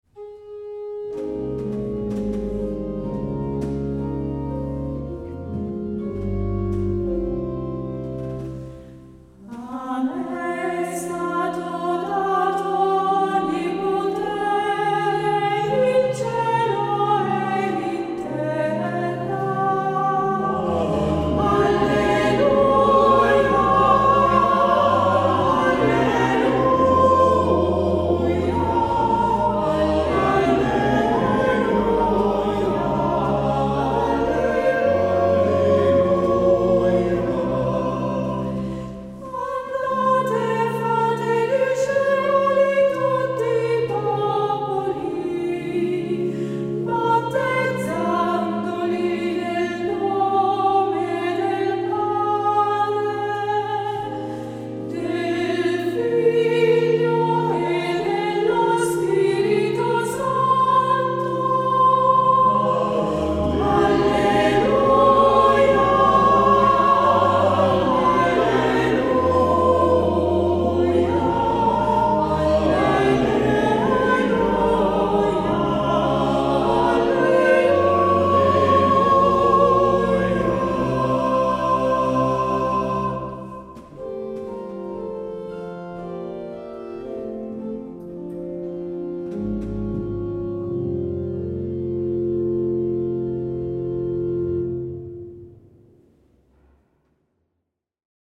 Versione a 4 voci
A-me-e-stato-dato-4-voci-Magistrali.mp3